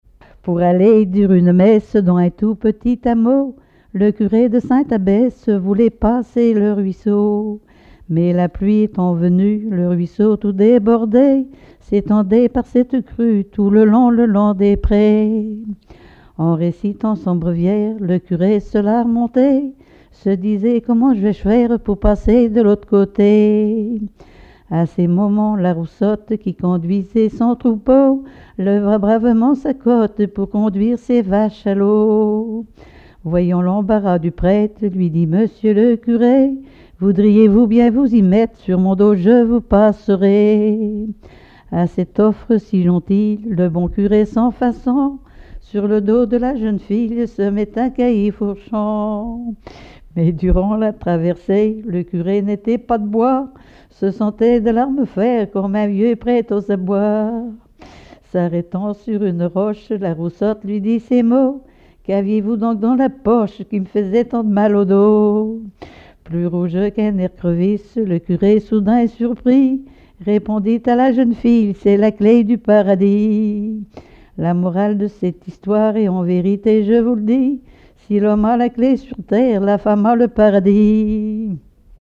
collecte en Vendée
Pièce musicale inédite